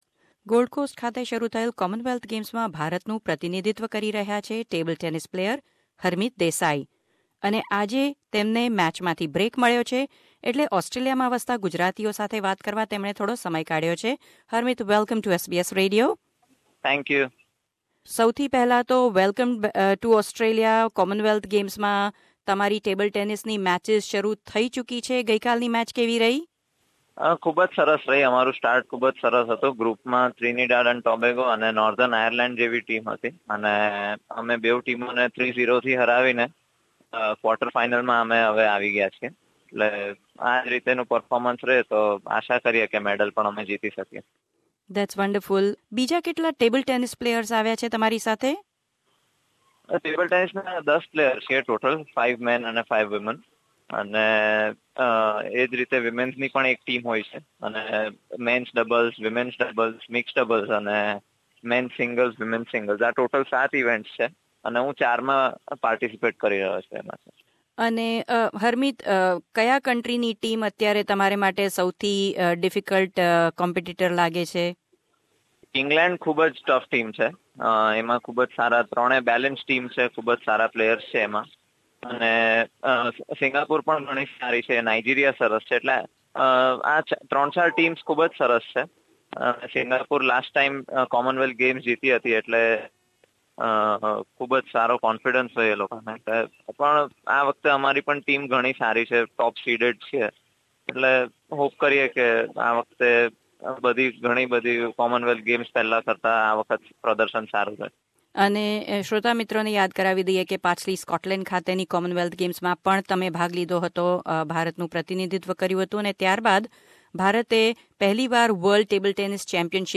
તૈયારી કેવી છે, ટીમનો મૂડ કેવો છે અને ગોલ્ડકોસ્ટમાં માહોલ કેવો છે? બે મેચ વચ્ચેના ખાલી દિવસમાં અમે વાત કરી હરમીત દેસાઈ સાથે.